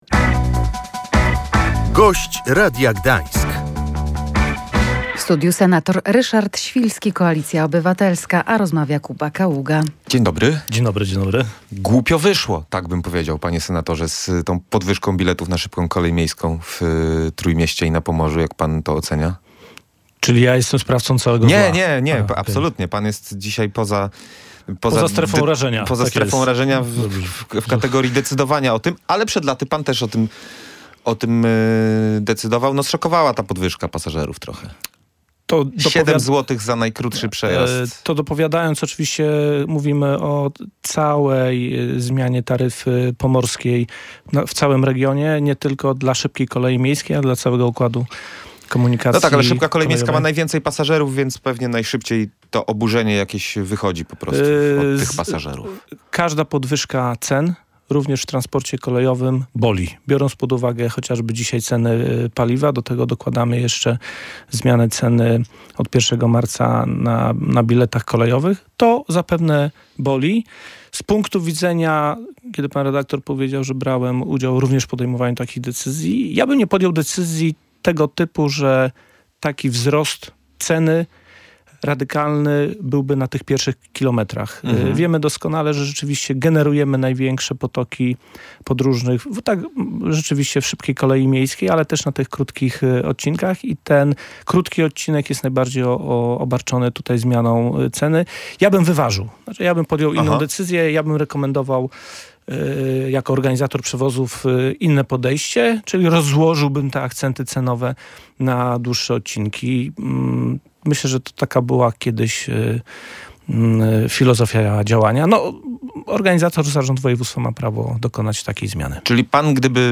Za tydzień rząd ma przyjąć ustawę metropolitalną dla Pomorza – zapowiedział w Radiu Gdańsk senator Koalicji Obywatelskiej Ryszard Świlski. Dzięki ustawie do naszego regionu mają trafić dodatkowe środki, między innymi na poprawę usług publicznych, w tym rozwój transportu.